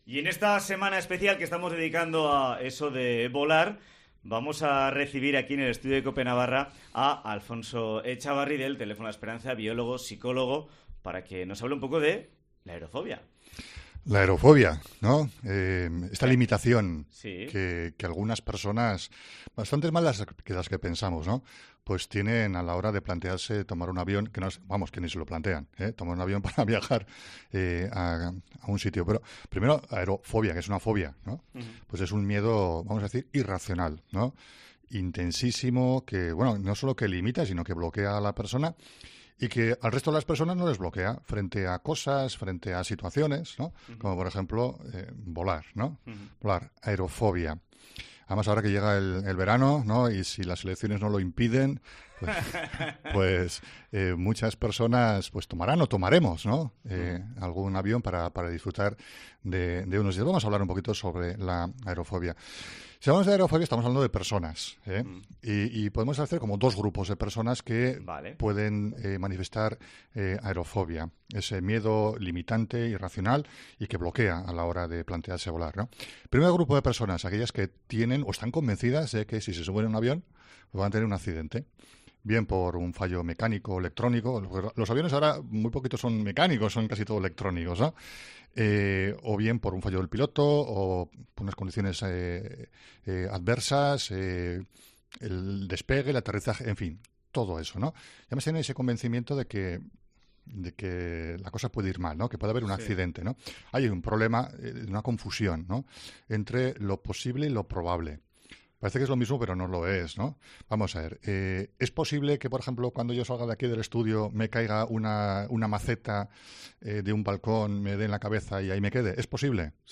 Entrevista
Semana dedicada en COPE Navarra a "volar".